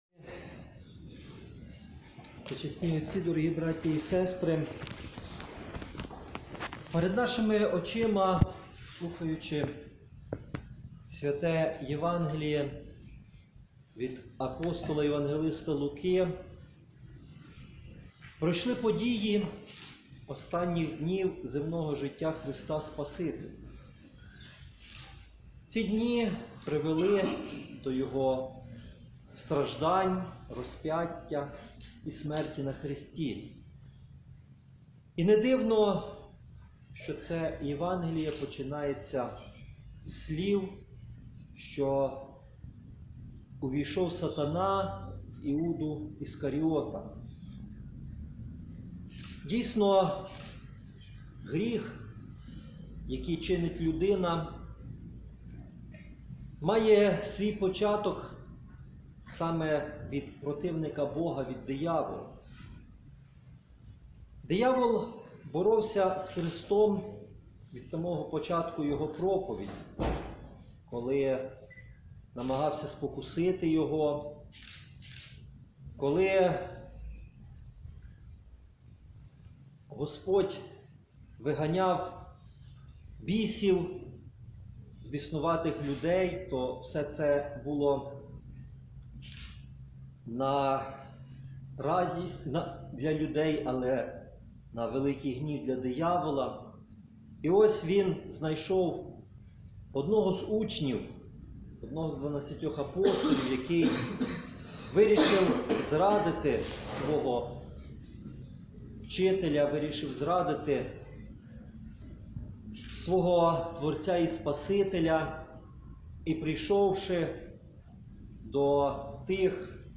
9 березня 2018 року, у п’ятницю другого тижня Великого посту, Високопреосвященнійший Нестор, архієпископ Тернопільський і Кременецький, звершив чин Пасії у каплиці кафедрального собору свв. рівноапостольних Костянтина та Єлени м. Тернополя.
Архієпископ Нестор під час богослужіня прочитав черговий фрагмент страсних Євангелій та звернувся до присутніх із відповідним повчанням.